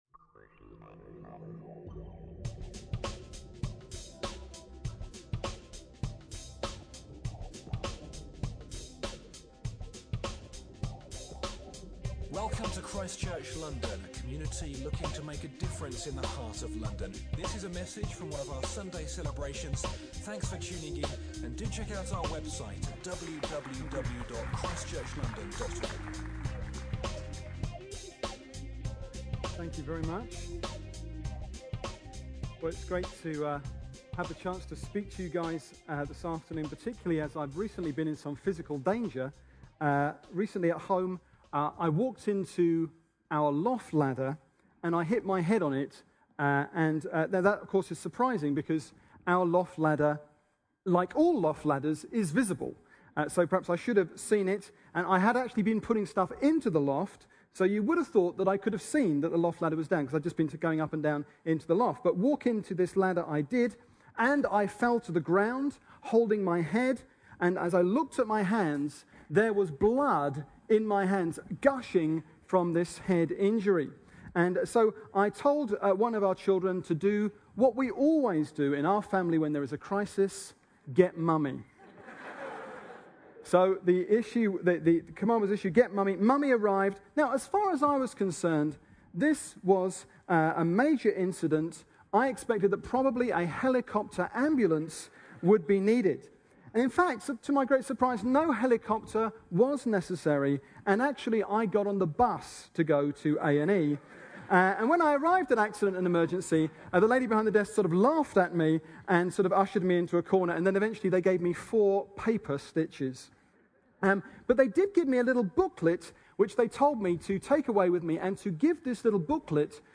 Preaching from ChristChurch London’s Sunday Service